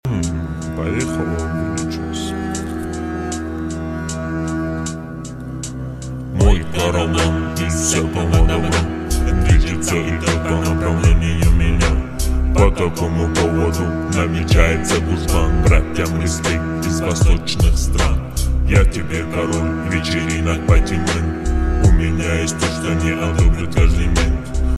Now Straightpiped khyber 🤟🏻 Wait sound effects free download